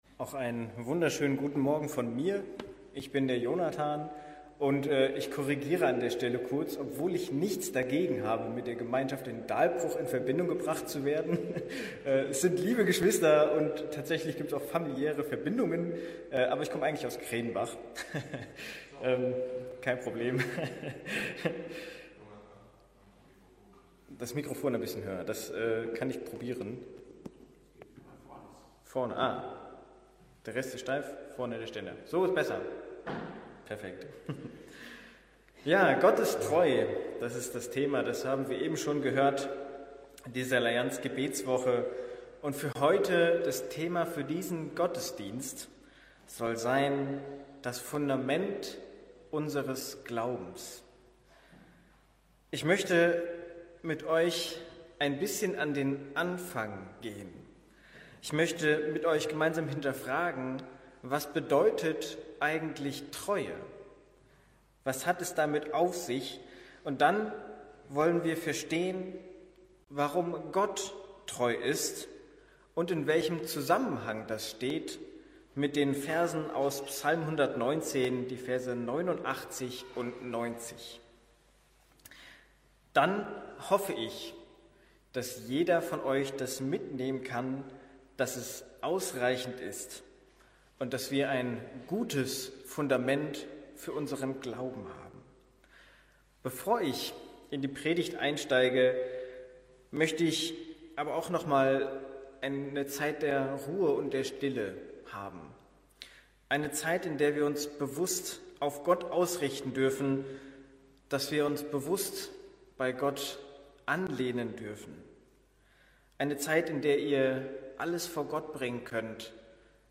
Predigten – Evangelische Gemeinschaft Kredenbach